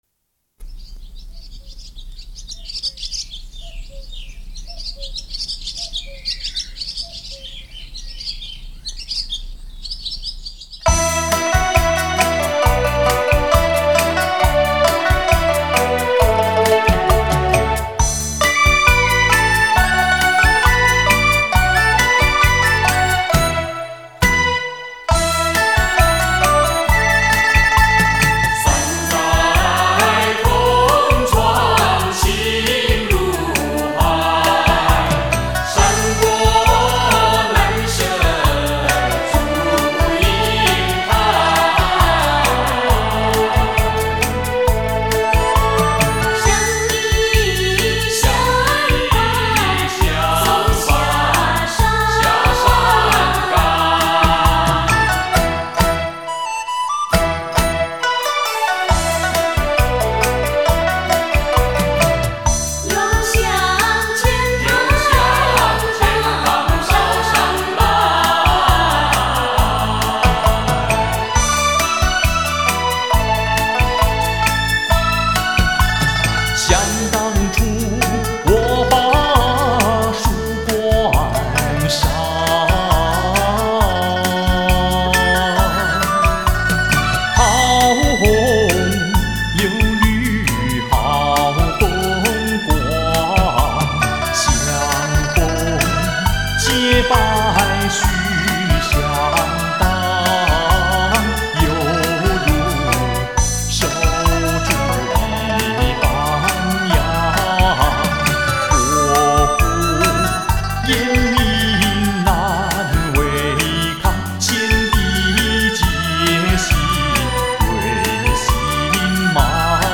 黄梅戏唱腔委婉清新，分花腔和平词两大类。
黄梅戏以高胡为主要伴奏乐器，加以其它民族乐器和锣鼓配合，适合于表现多种题材的剧目。
[mjh4][light]那歌声...那旋律...悠扬飘荡...[/light][/mjh4]